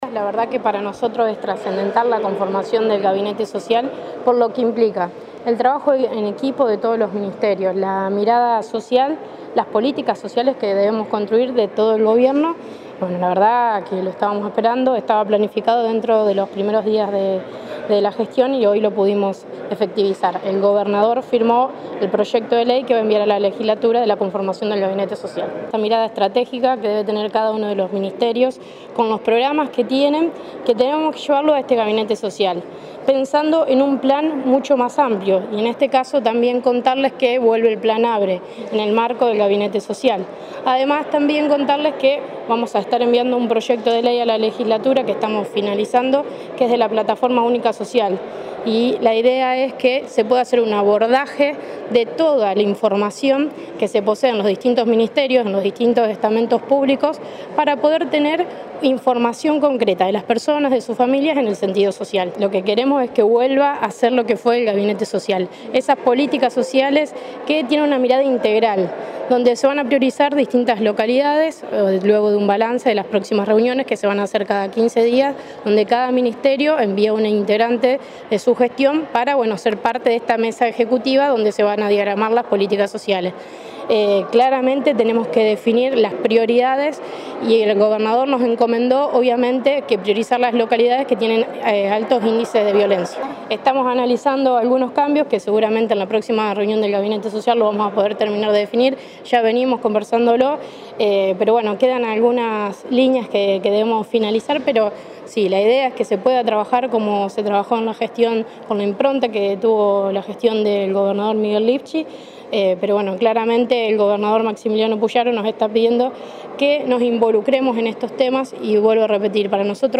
Delcaraciones de la ministra Tejeda